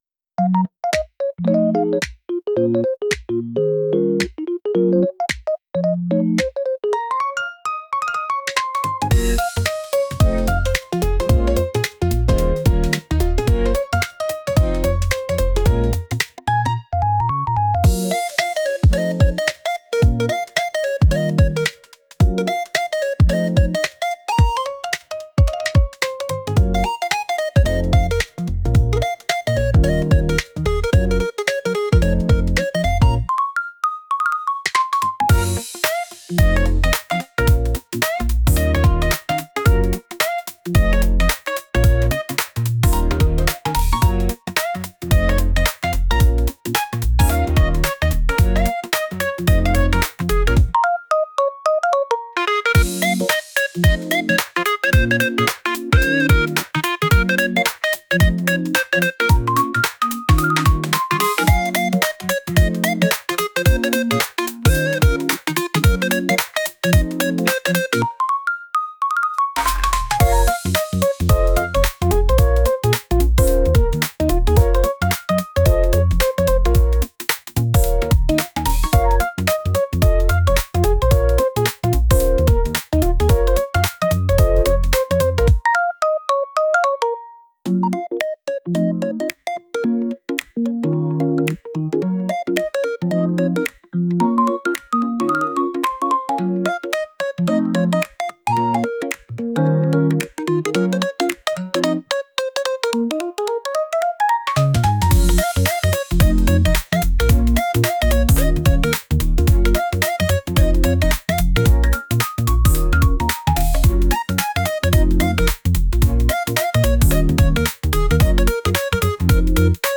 あやしい かっこいい ポップ